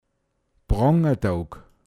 pinzgauer mundart
Fronleichnam Prångatåog, m.